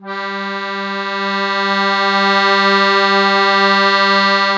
MUSETTE1.1SW.wav